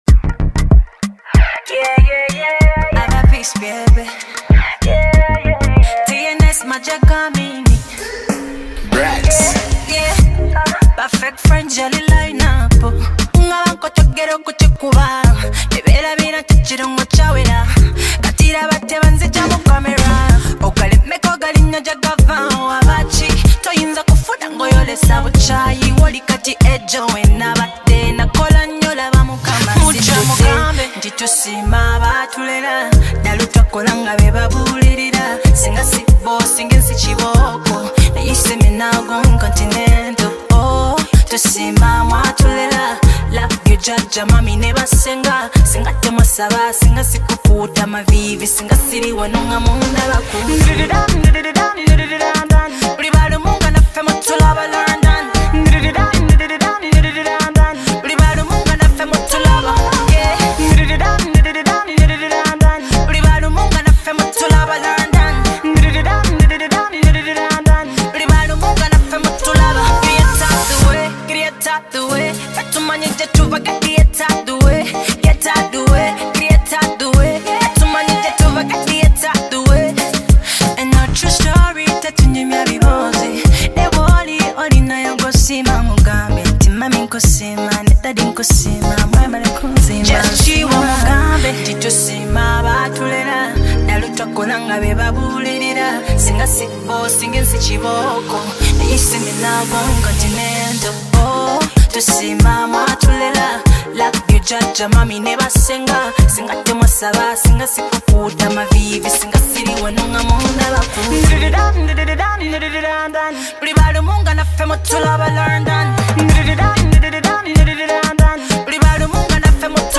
infectious Afro-fusion single
Genre: Dancehall